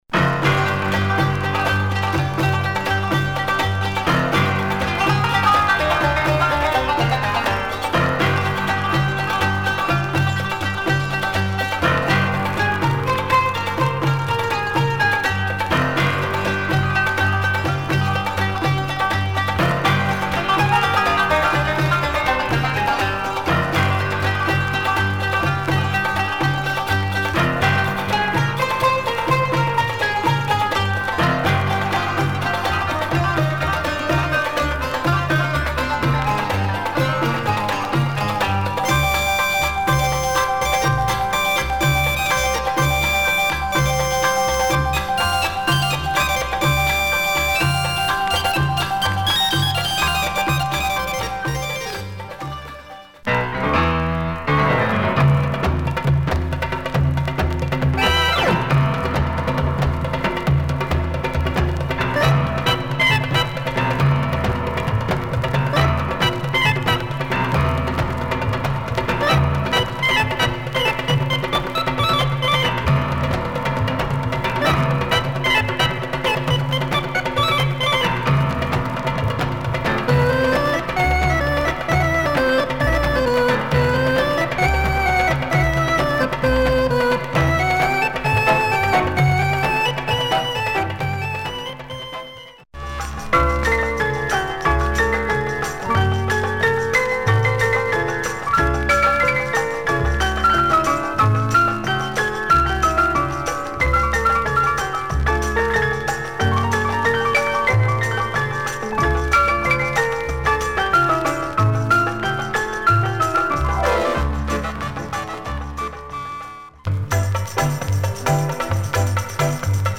Ultra rare Lebanese jazz
It is Oriental folklore mixed with jazz
belly dance beats + moog and piano jazz…